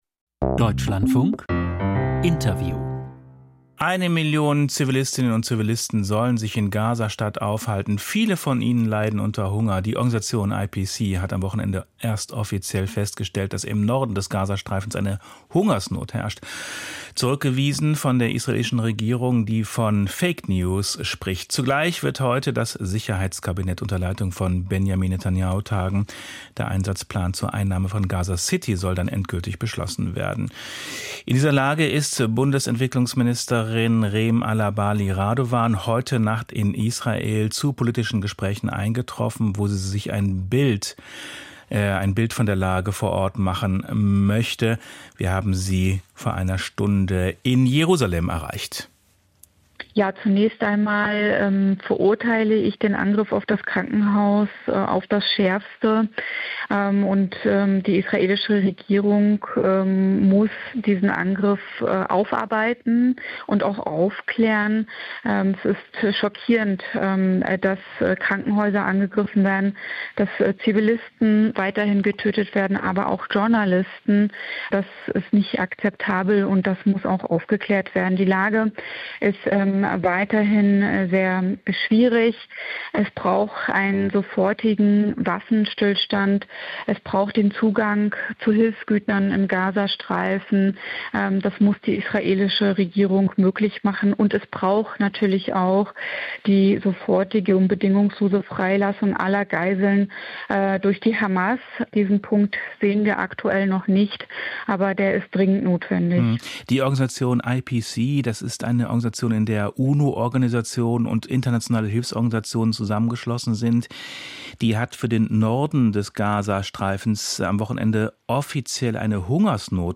Interview Reem Alabali Radovan, Bundesentwicklungsministerin